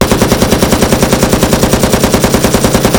Sound effect
机枪（新）.wav